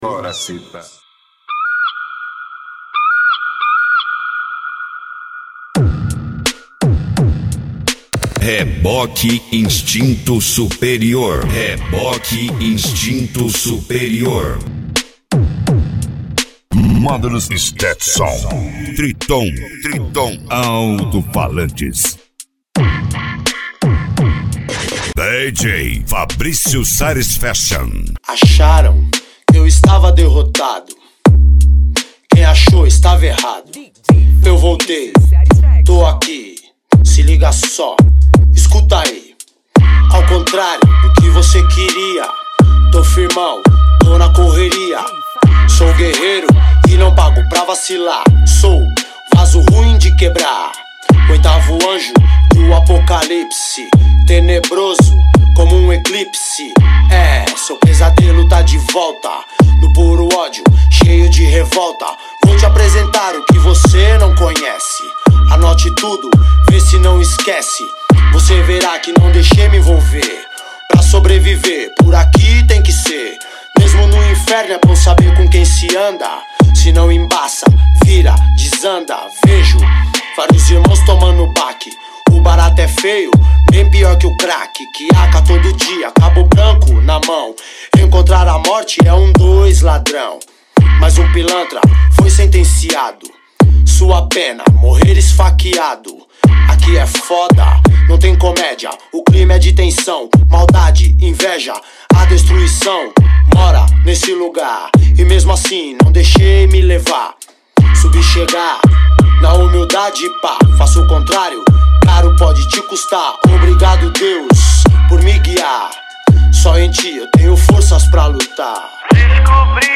Bass
Mega Funk